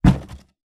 Punching Box Intense B.wav